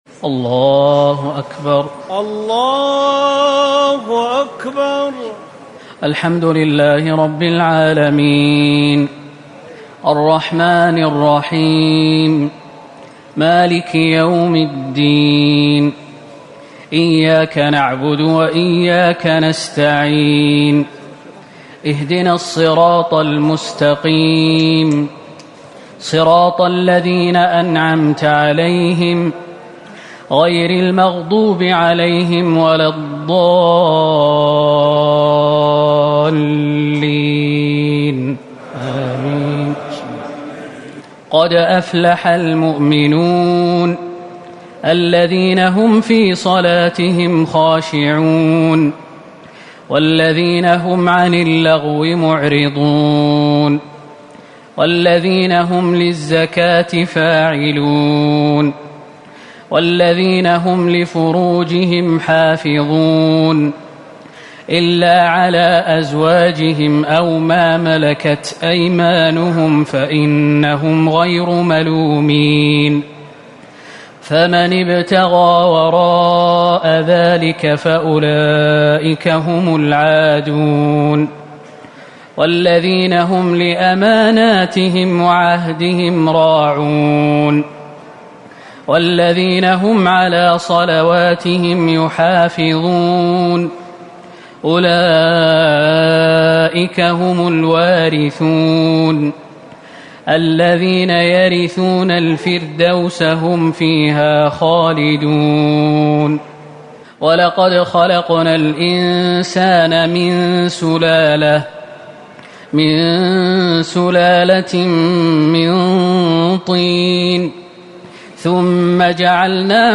ليلة ١٧ رمضان ١٤٤٠ سورة المؤمنون والنور ١-٢٠ > تراويح الحرم النبوي عام 1440 🕌 > التراويح - تلاوات الحرمين